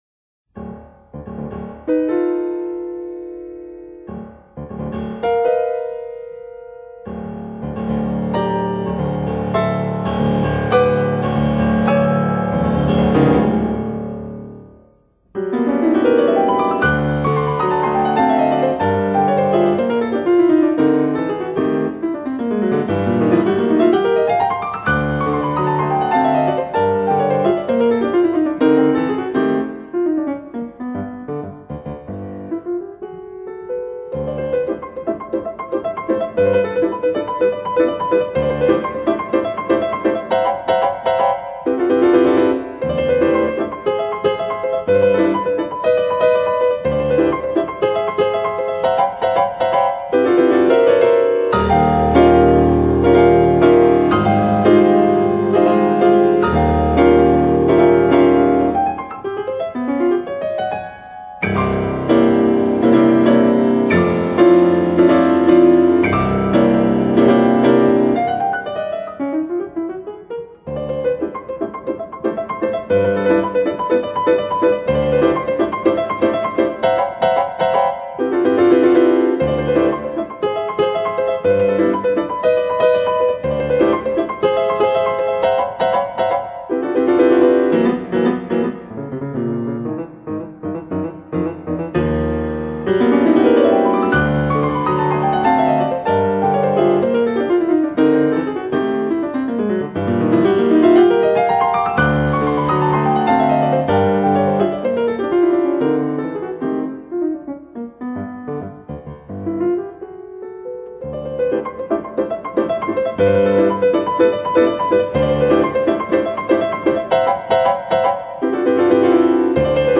música académica uruguaya